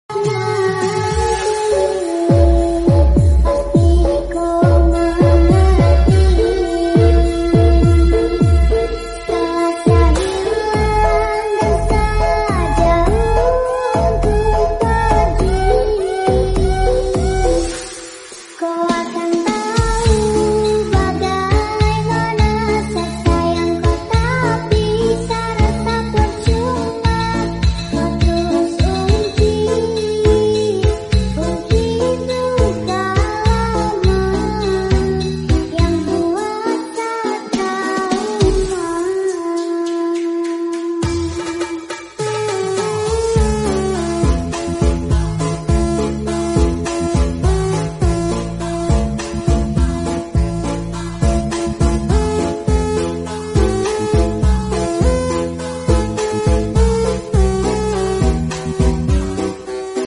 full bass